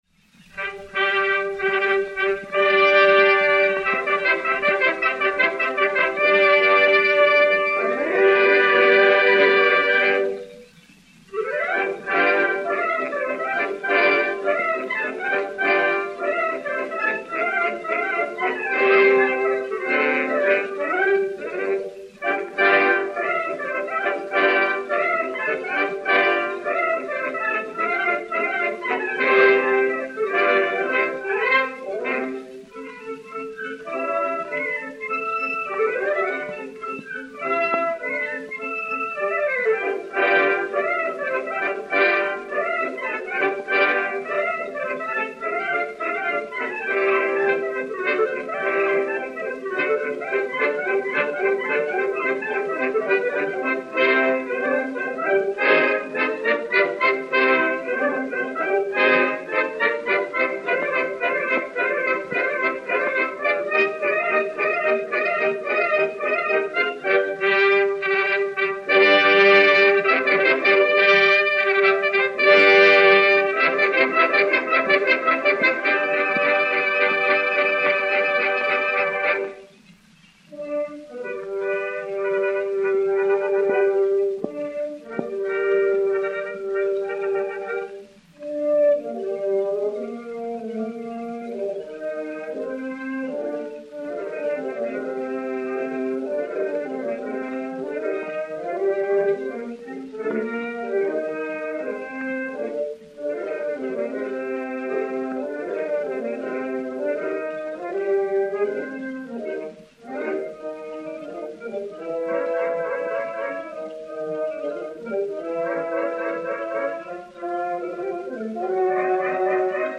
Musique de la Garde Républicaine dir César Bourgeois
Pathé saphir 80 tours n° 6215, mat. 5249 et 5265 et n° 6352, mat. 5250 et 5266, enr. vers 1910